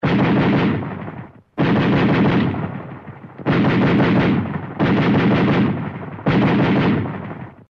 Звук пострілів автоматичної гвинтівки